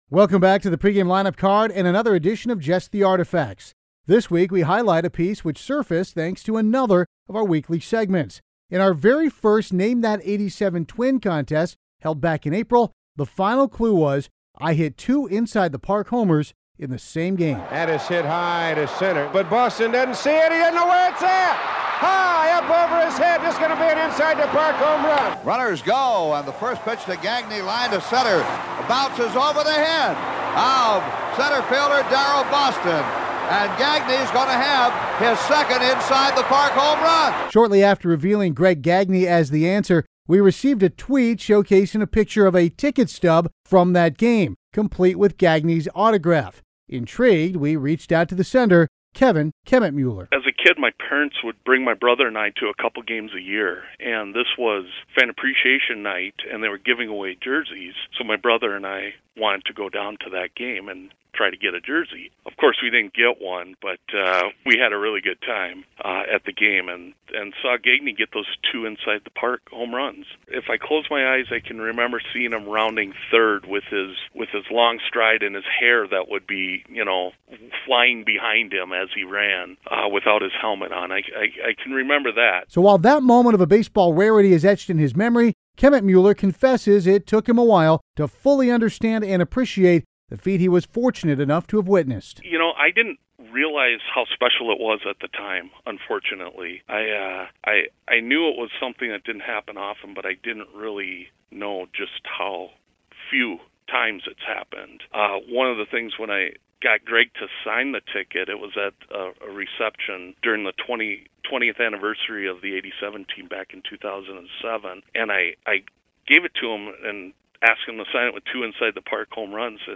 As a follow up to a tweet I posted as a reply to a Twins Radio Network post, I was contacted and asked to discuss a signed ticket I have in my collection. Here is the interview as it aired on the Twins Pregame Radio show on July 7, 2017.